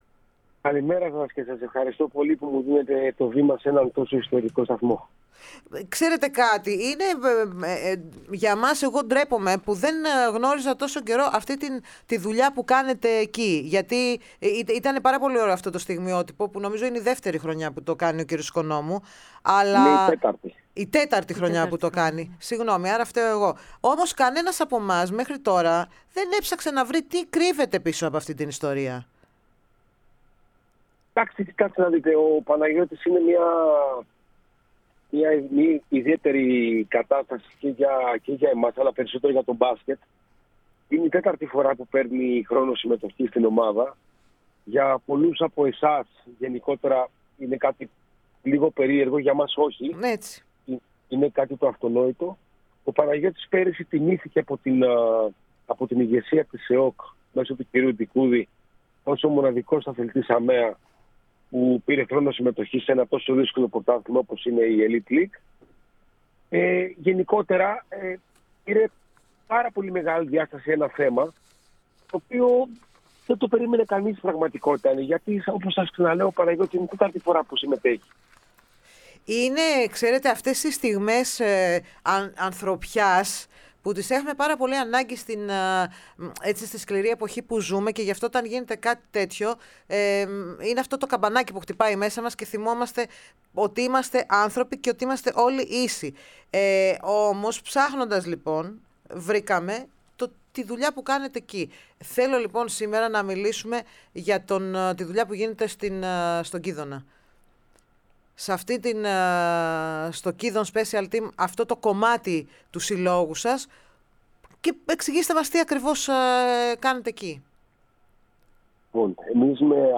Η ΕΡΑ ΣΠΟΡ φιλοξένησε στην εκπομπή "Οι Τρισδιάστατοι"